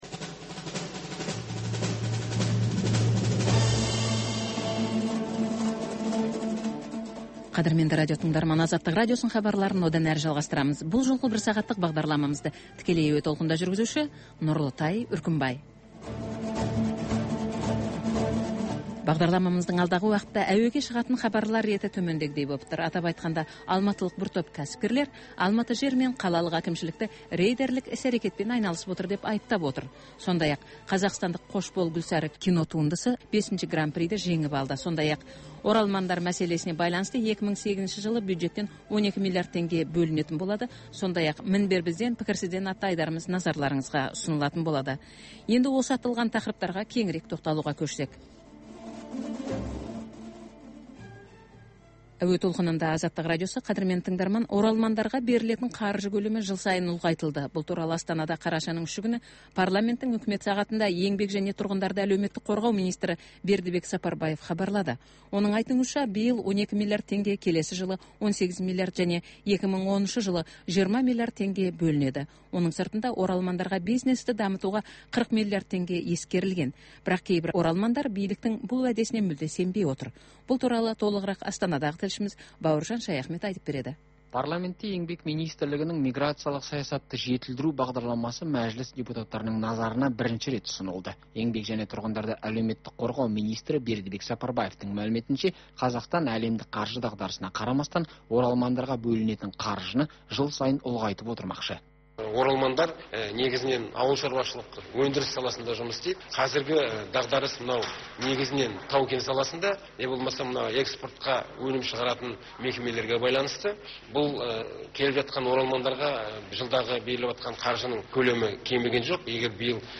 Бүгінгі күннің өзекті тақырыбына талқылаулар, оқиға ортасынан алынған репортаж, пікірталас, қазақстандық және халықаралық сарапшылар пікірі.